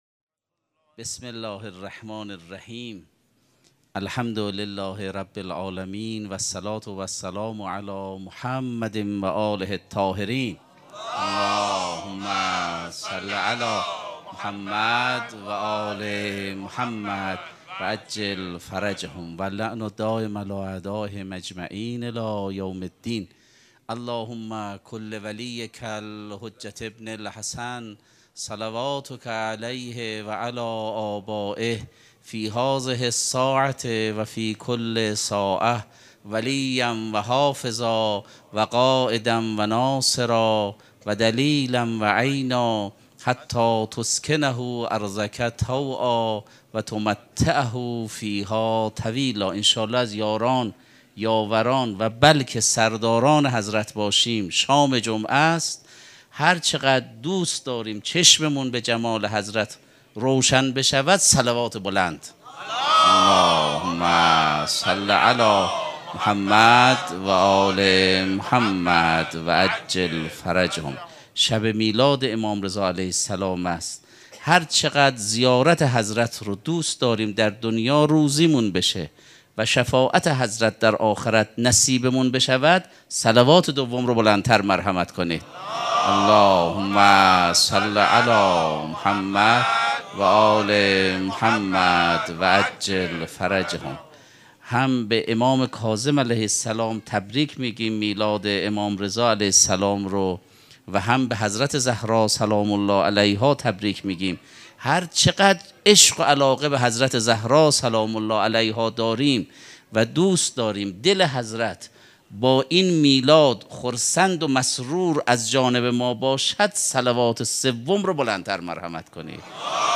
سخنرانی
sokhanrani.mp3